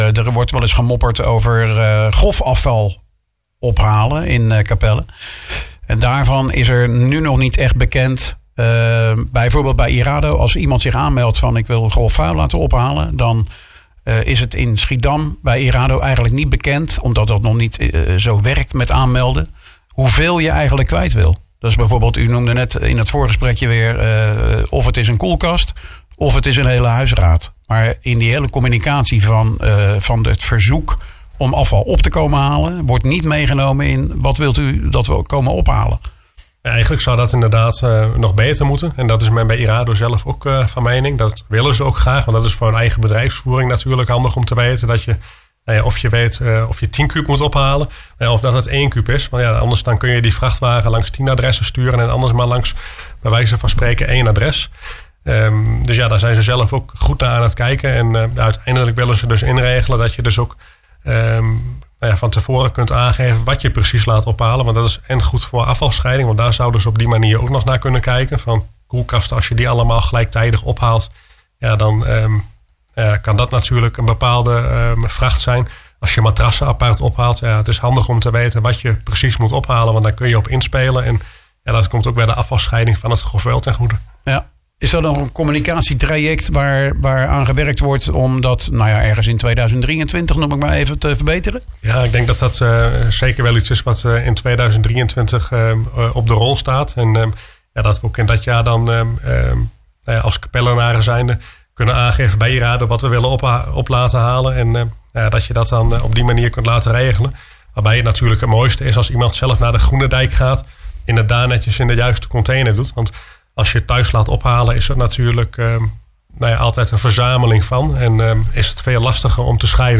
in gesprek met wethouder Sjoerd Geissler.